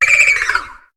Cri de Froussardine dans sa forme Solitaire dans Pokémon HOME.